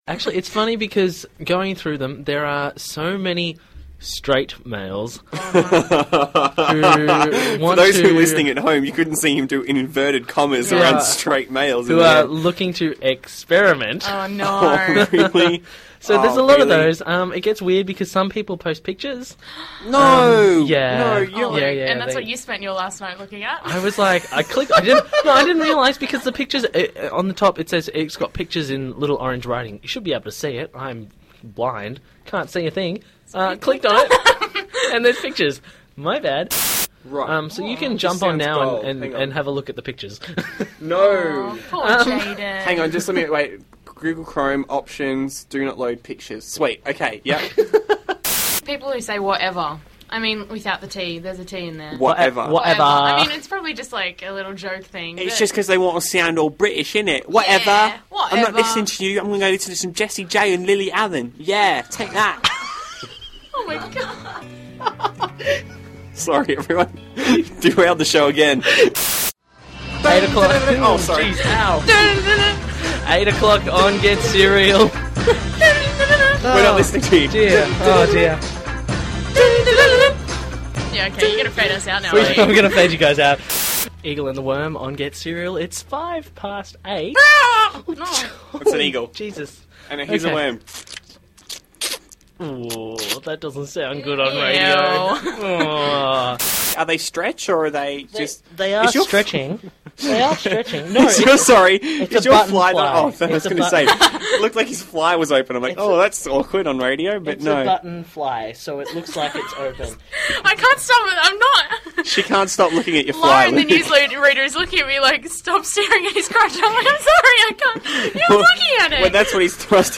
I filled in on Get Cereal, and I'm not sure they were quite expecting me to be so energetic!